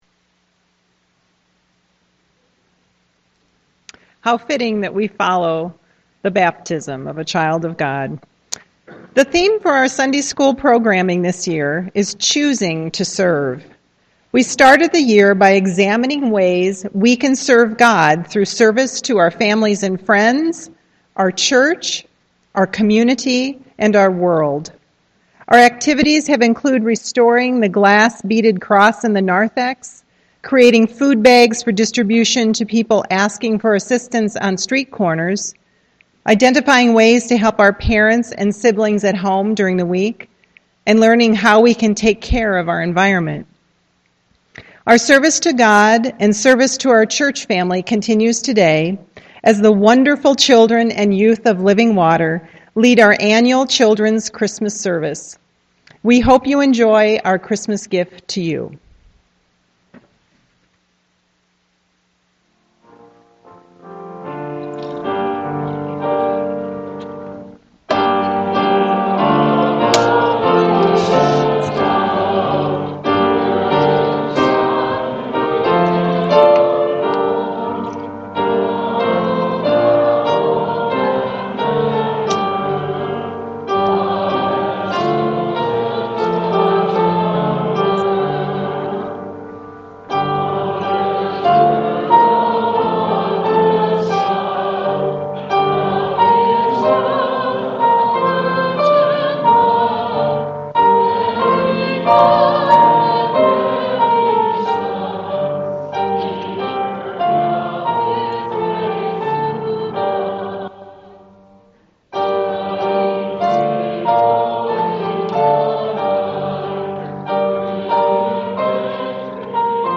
Children’s Christmas Service